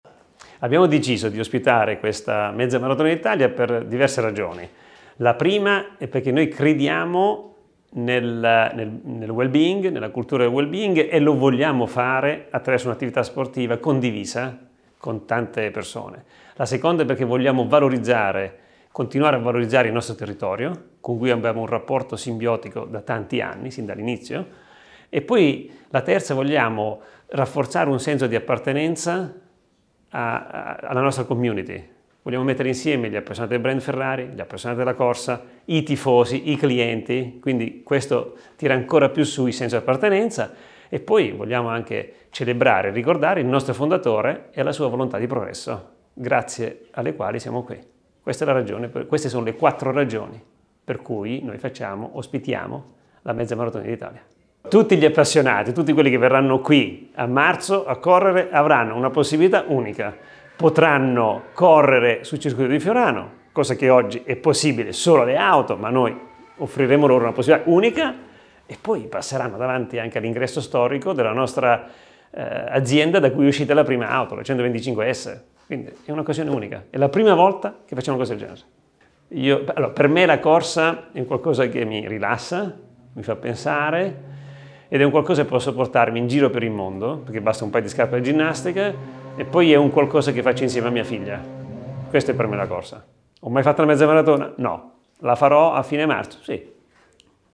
Ce ne parla Benedetto Vigna, Amministratore delegato di Ferrari: